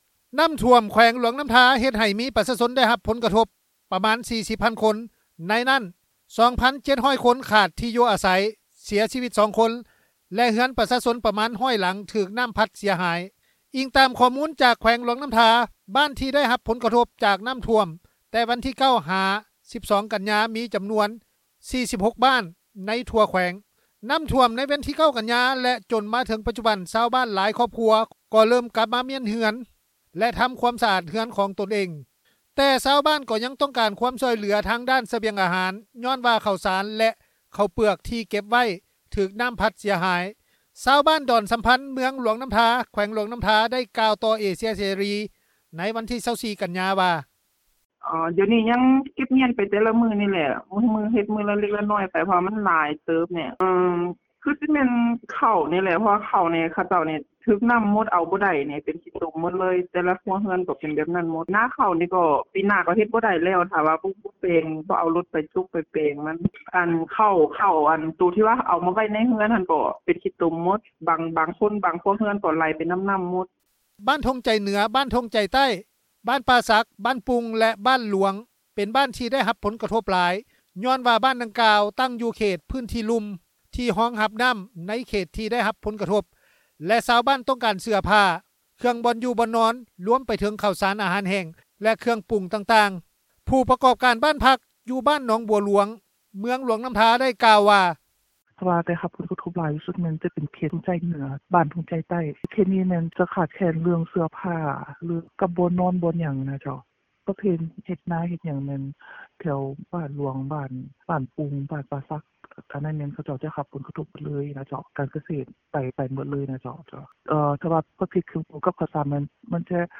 ຊາວບ້ານດອນສໍາພັນ ເມືອງຫລວງນໍ້າທາ ແຂວງ ຫລວງນໍ້າທາ ໄດ້ກ່າວຕໍ່ເອເຊັຽເສຣີ ໃນວັນທີ 24 ກັນຍາ ວ່າ:
ເຈົ້າໜ້າທີ່ກ່ຽວຂ້ອງຫ້ອງວ່າການແຂວງຫລວງນໍ້າທາ ໄດ້ກ່າວວ່າ: